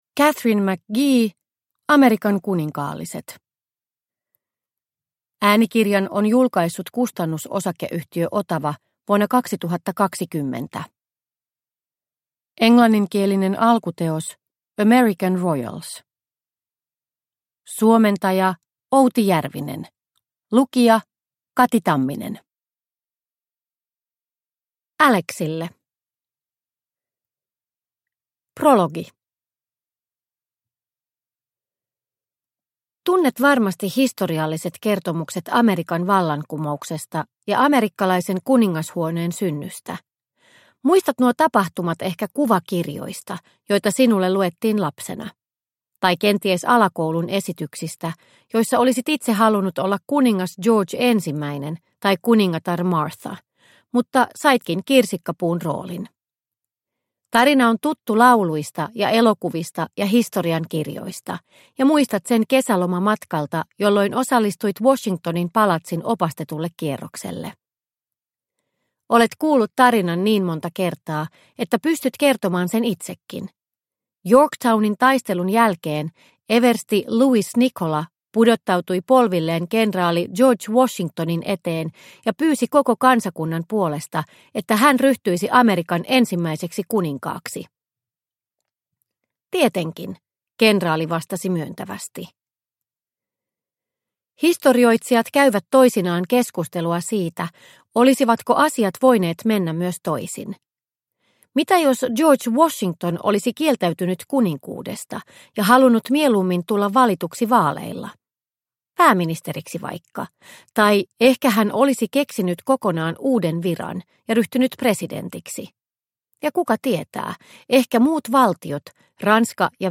Amerikan kuninkaalliset – Ljudbok – Laddas ner